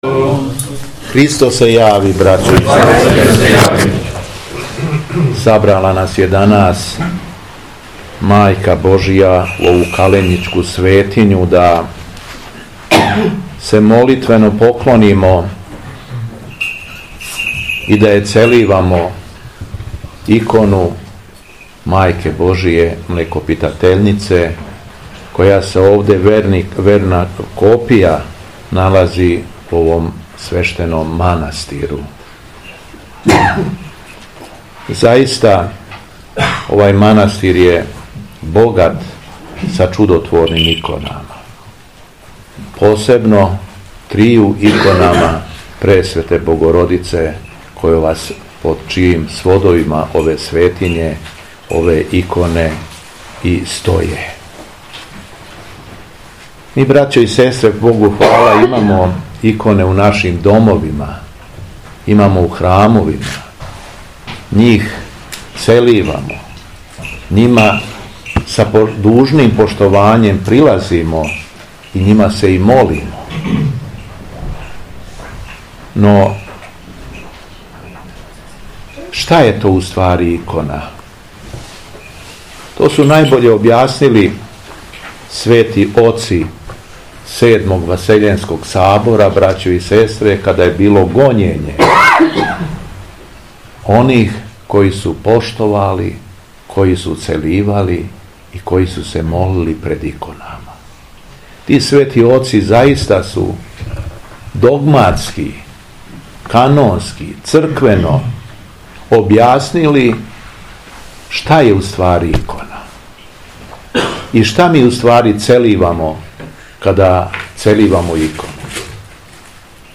Беседа Његовог Преосвештенства Епископа шумадијског г. Јована
По прочитаном Јеванђелском зачалу Преосвећени се обратио присутнима:
У четвртак 25. јануара, када наша Света Црква прославља икону Млекопитатељнице и Свету мученицу Татијану, Његово Преосвештенство епископ шумадијски Господин Јован, служио је Свету Архијерејску Литургију у манастиру Каленић.